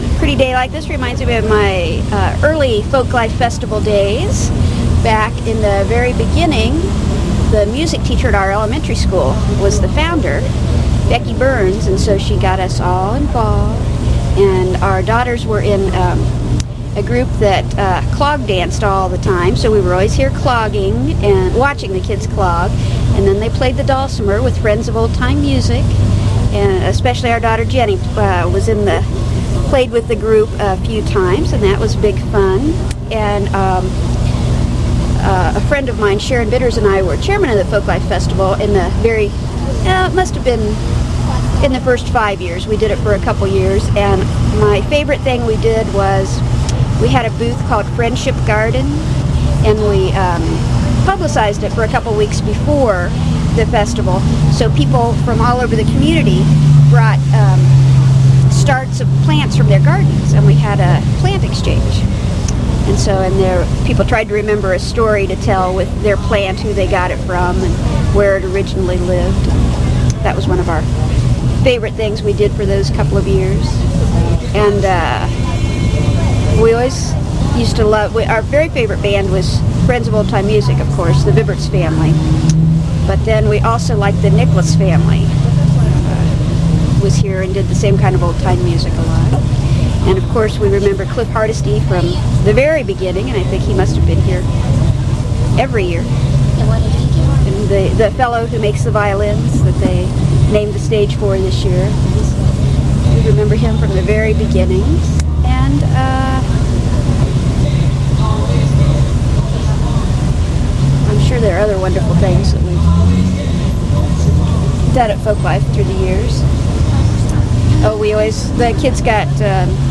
Oral History
with genre interview.